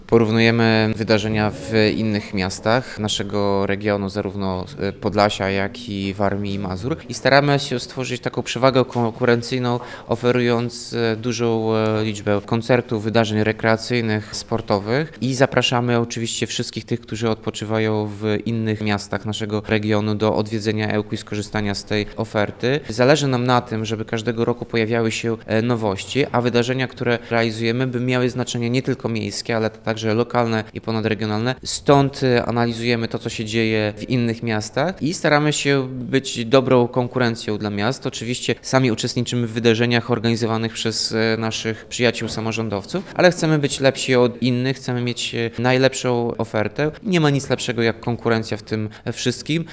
– Porównując Ełk z Giżyckie, Augustowem, czy Oleckiem możemy powiedzieć, że nasza oferta jest bardzo dobra i do tego różnorodna- dodaje Andrukiewicz.